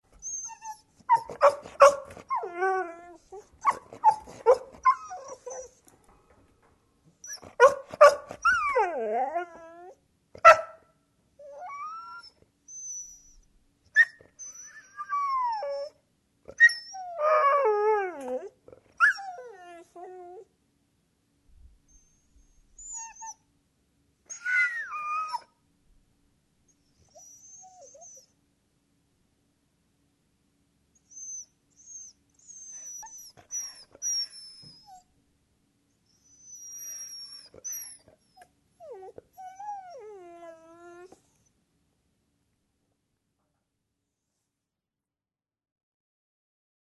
Грустный лай собаки (скулит)